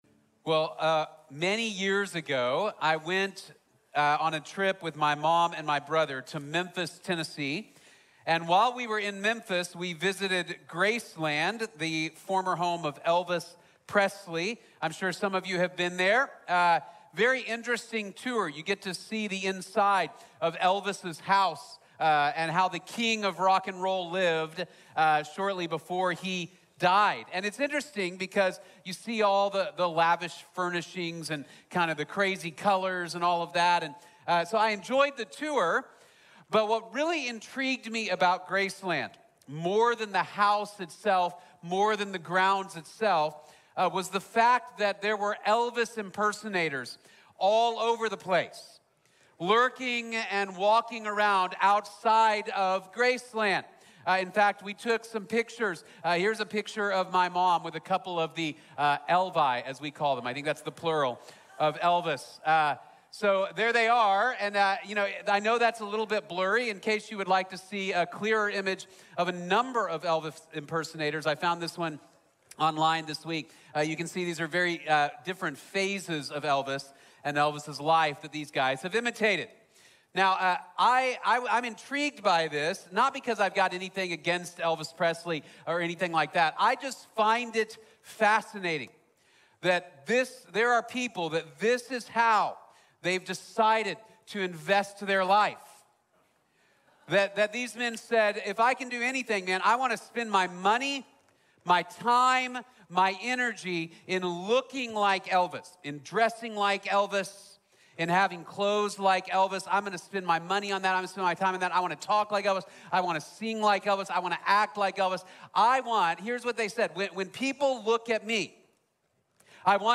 The Motivation and Meaning of Discipleship | Sermon | Grace Bible Church